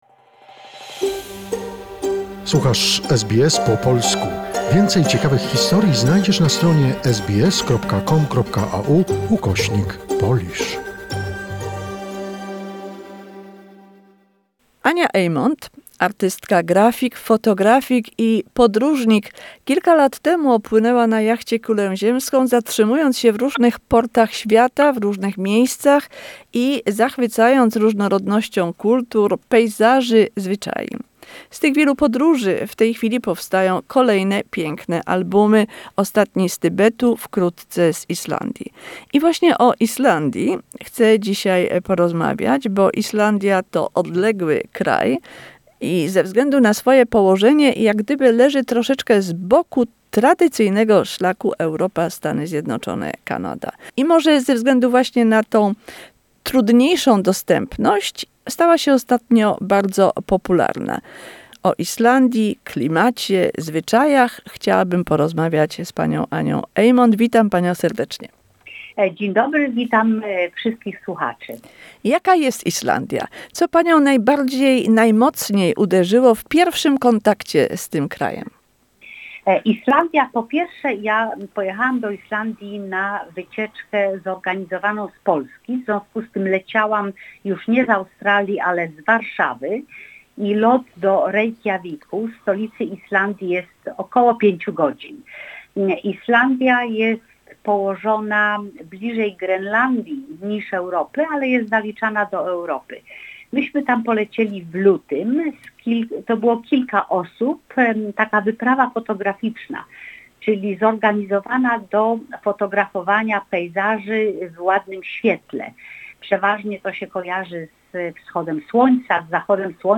Second part of the conversation